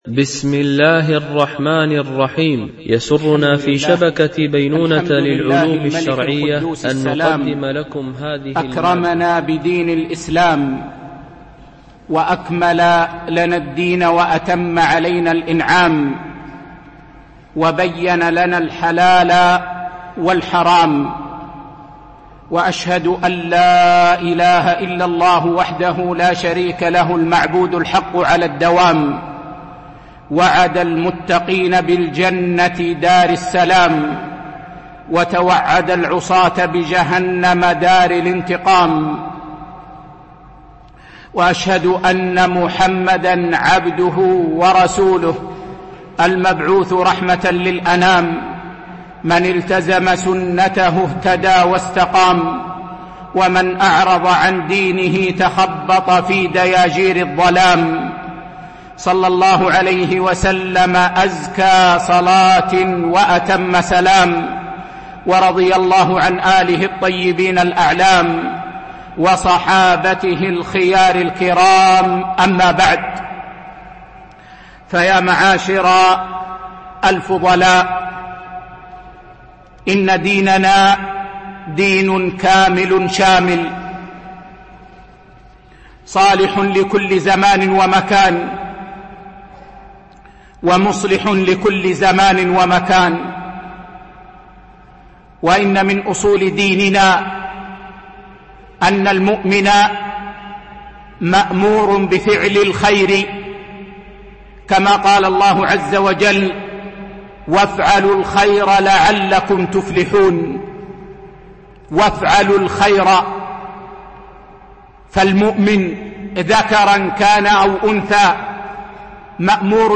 محاضرة: أبواب الخير في القرآن والسنة النبوية